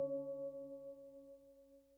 sonarTailAirClose2.ogg